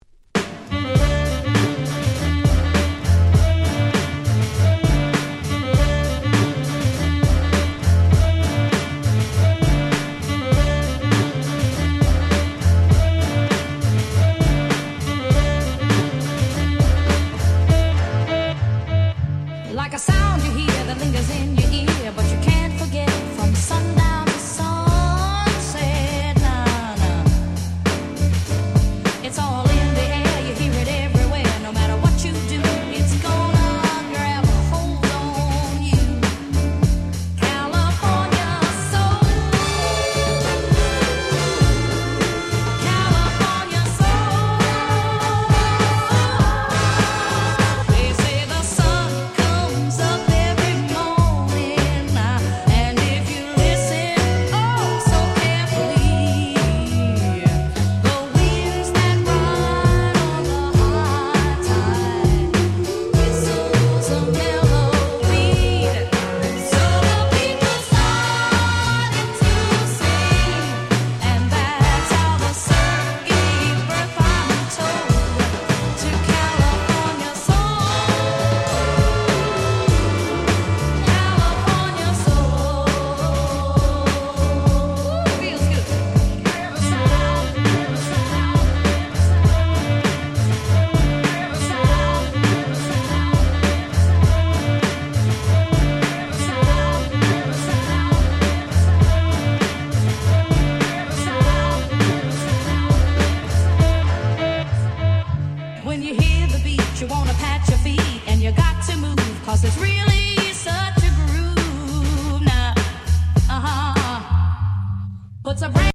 21' Nice Remix !!